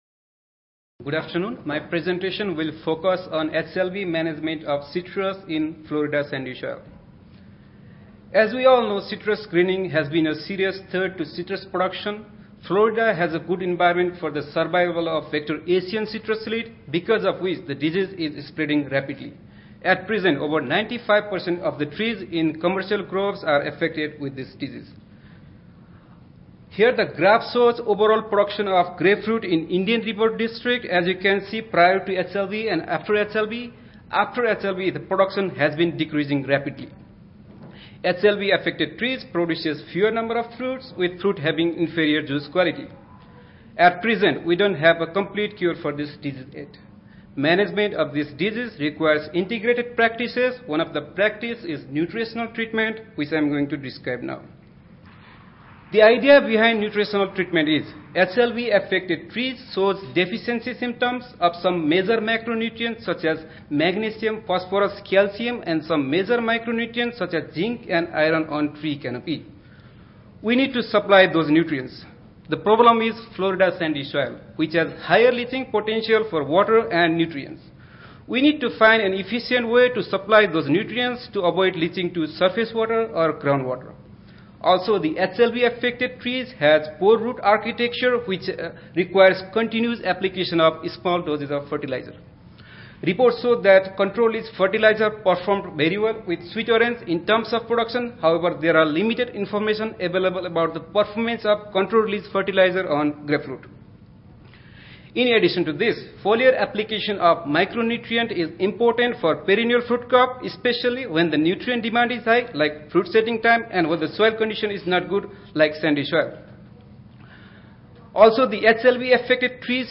University of Florida Audio File Recorded Presentation